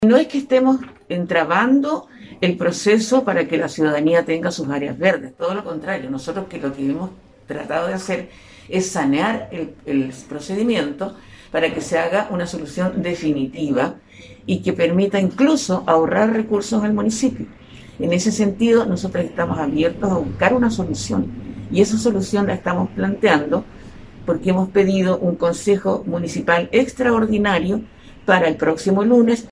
De forma paralela, la edil comunista, Olimpia Riveros, aseguró que el concejo no quiere afectar el cuidado de las áreas verdes y por eso pidieron al alcalde Ortiz una reunión extraordinaria donde se analice un trato directo por sólo 4 meses.